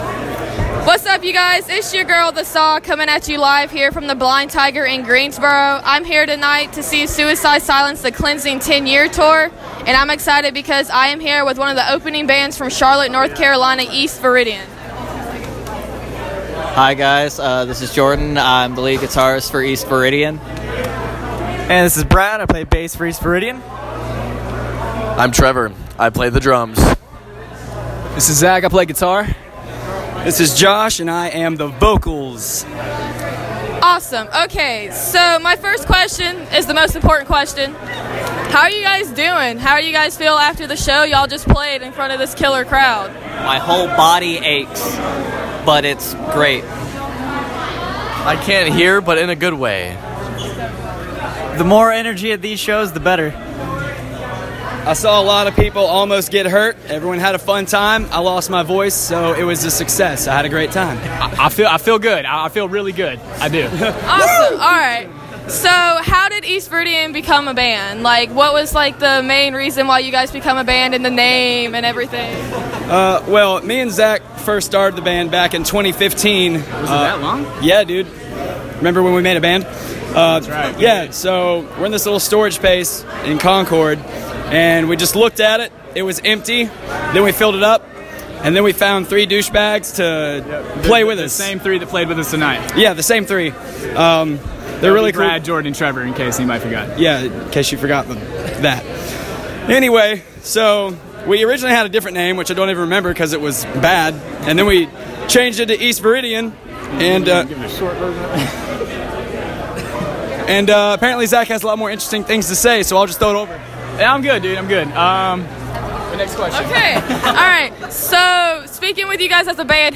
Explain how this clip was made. Long story short, I got to interview the band after the Suicide Silence show. They are nice guys, and I really enjoyed talking to them.